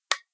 switch8.ogg